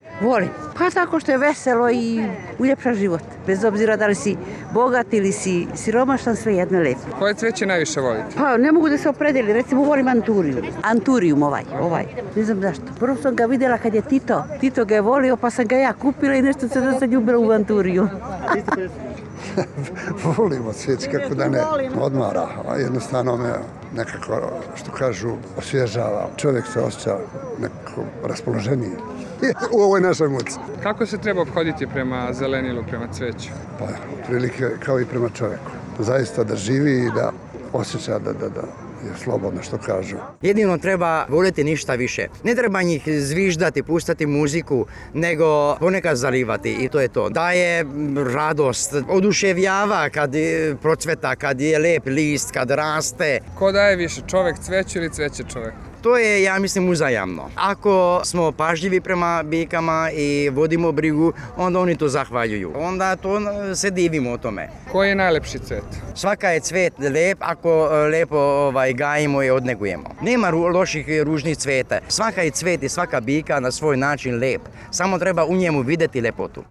U beogradskom parku Manjež, koji je ovih dana lepši i mirišljaviji nego ikada, otvorena je izložba cveća.
Posetioci i uzgajivači o cveću